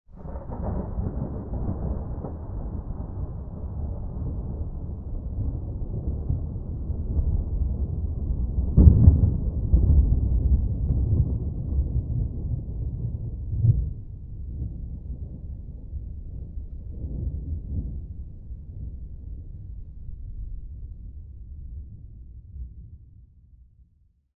thunderfar_2.ogg